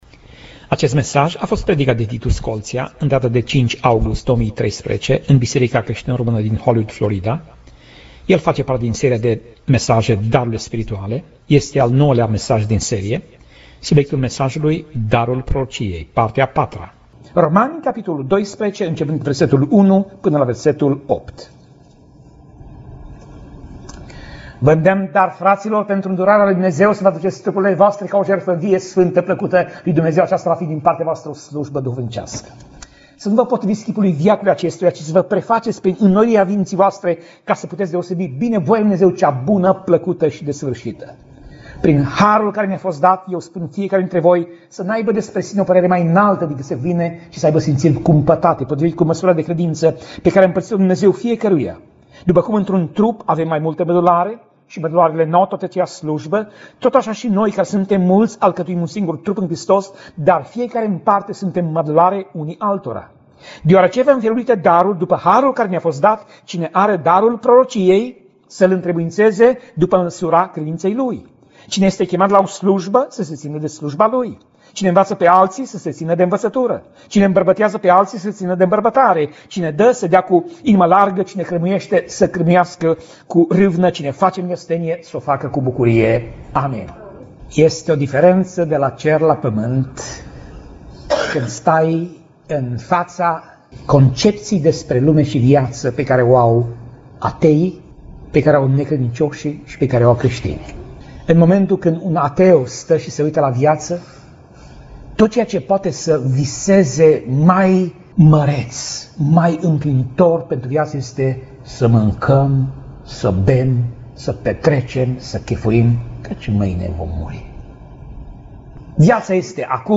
Pasaj Biblie: Romani 12:1 - Romani 12:8 Tip Mesaj: Predica